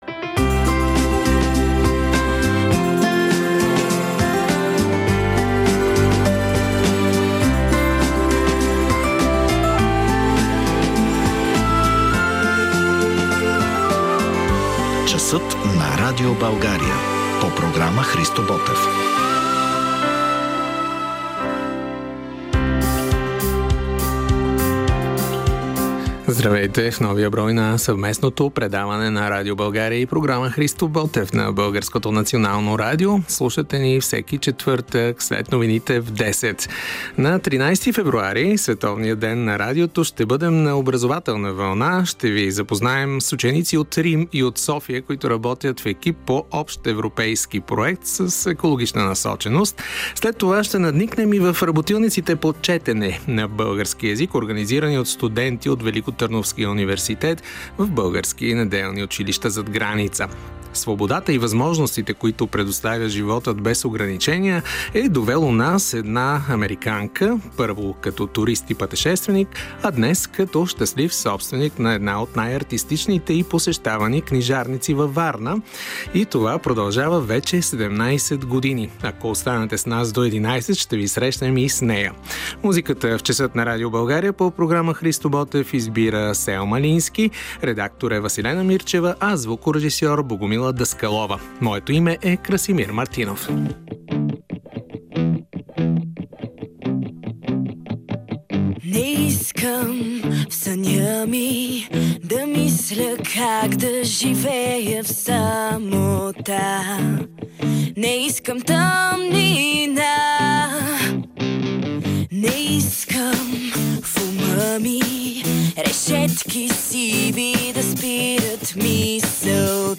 В брой 39 от Часът на Радио България, излъчен на 13 февруари 2025: